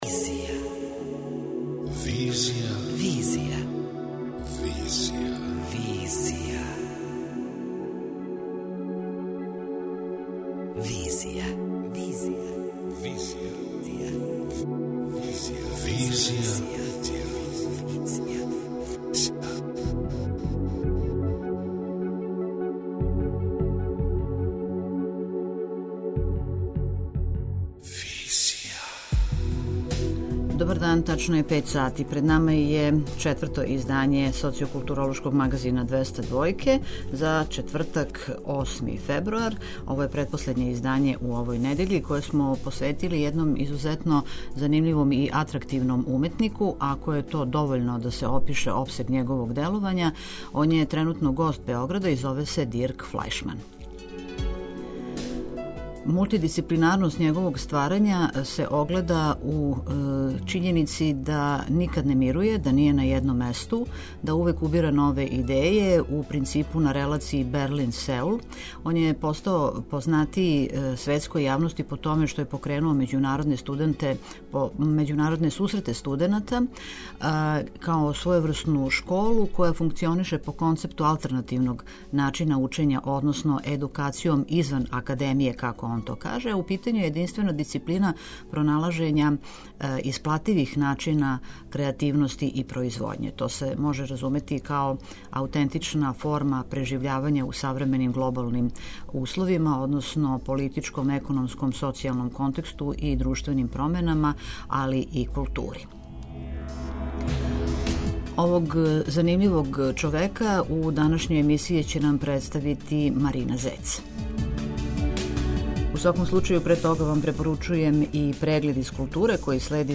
Самоодржива култура - разговор